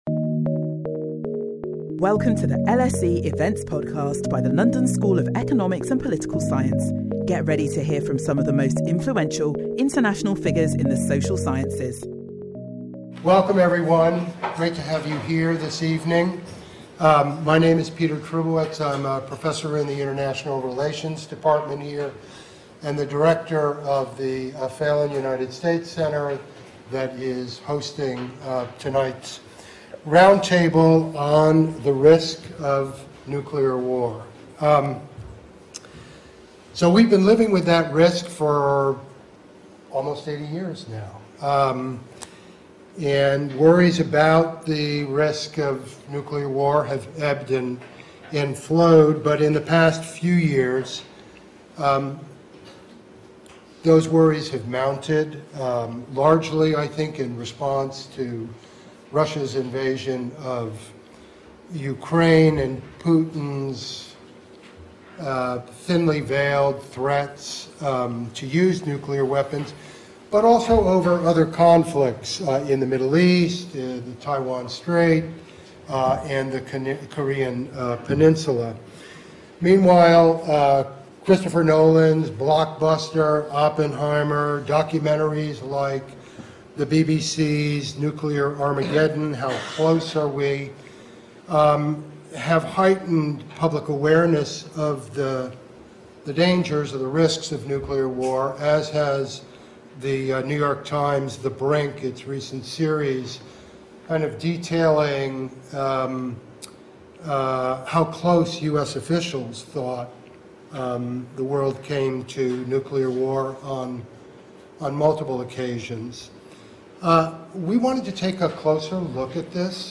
As Nuclear security issues and risks are back on the international agenda, our panel examine the steps that can be taken to reduce them.